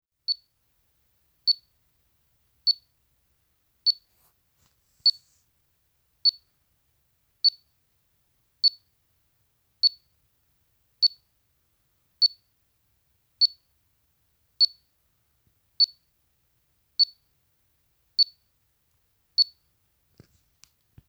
Der Sender sendet kurze Pieptöne (siehe Hörbeispiele), es wäre aber möglich, dass gegen Ende der Batterielaufzeit die Impulse kürzer und seltener werden, um Energie zu sparen. leider haben wir keine verbindlichen Angaben, in welcher Modulationsart gesendet wird – ich vermute dass es ein unmodulierter Träger ist, der also als CW oder SSB demoduliert werden kann.